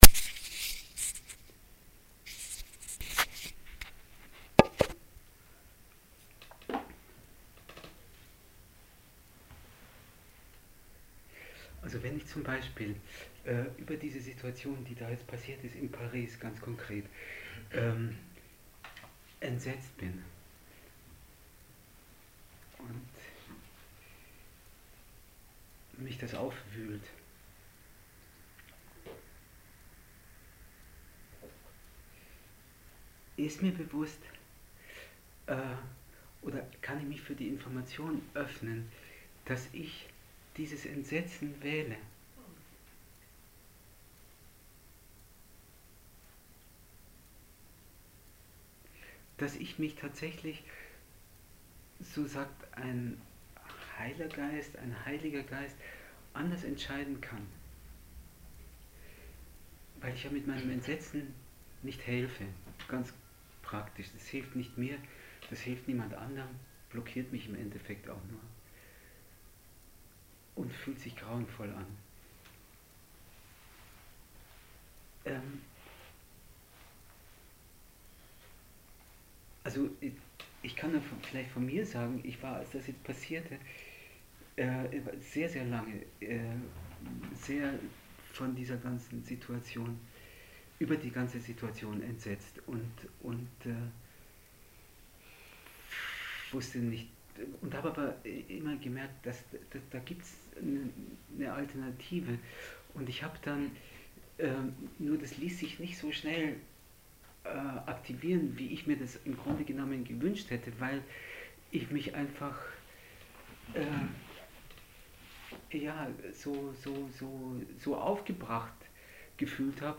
Ausschnitt eines Treffens vom 7. Februar 2016